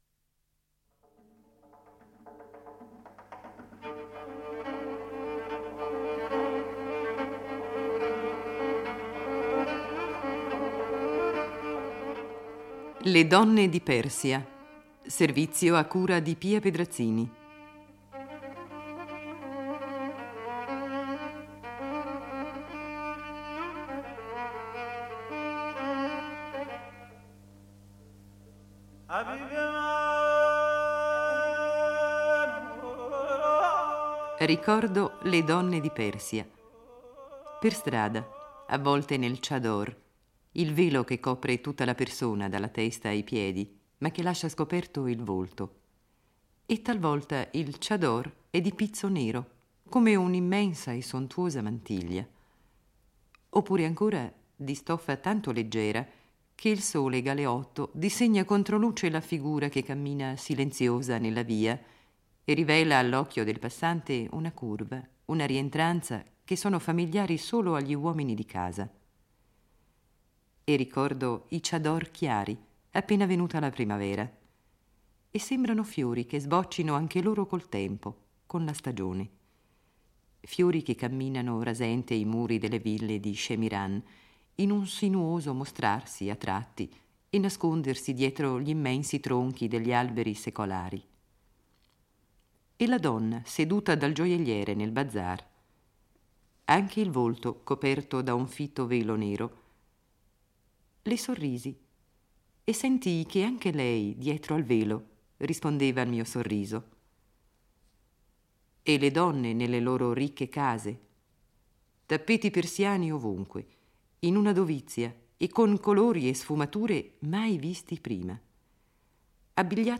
Le voci delle intervistate raccontano un Paese in trasformazione: le donne conquistano nuovi diritti, dall’accesso all’università allo sport, mentre nascono associazioni femminili sostenute anche dalla regina Farah Diba, impegnata in iniziative per l’infanzia e la gioventù. Il servizio approfondisce anche la presenza femminile in Parlamento e le riforme sul divorzio che, pur rappresentando un progresso, continuano a favorire gli uomini.